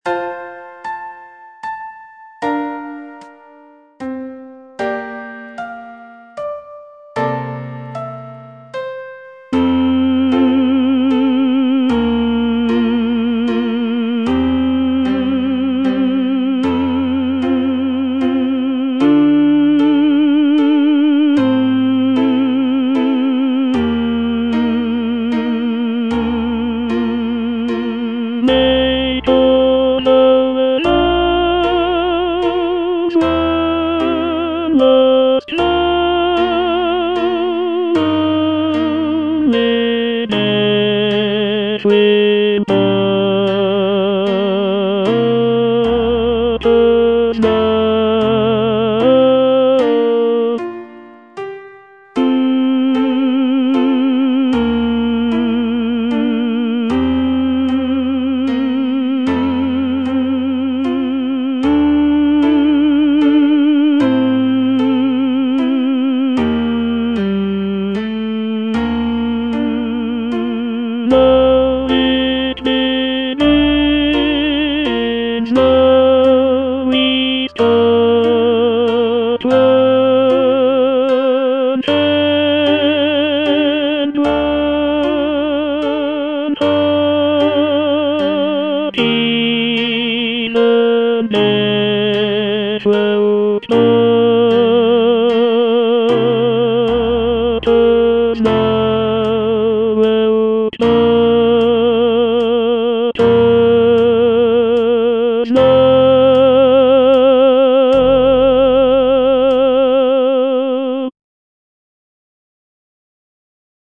Tenor (Voice with metronome) Ads stop